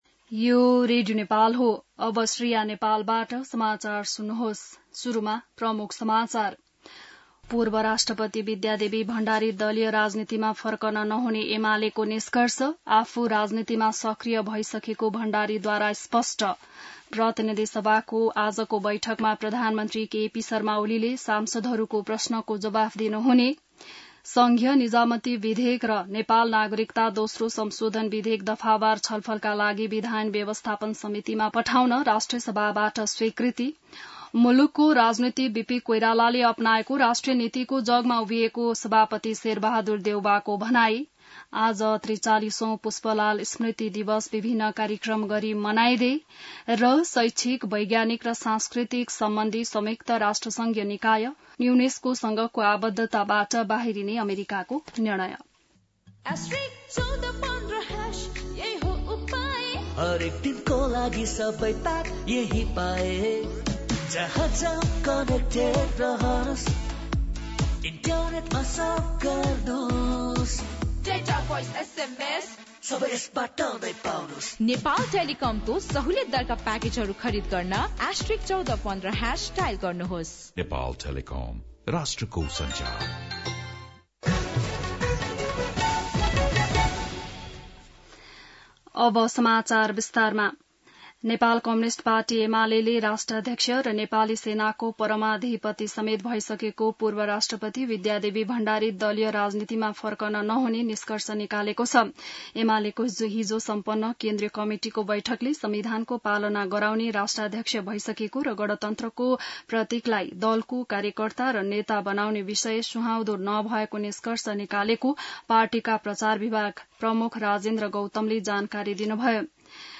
बिहान ७ बजेको नेपाली समाचार : ७ साउन , २०८२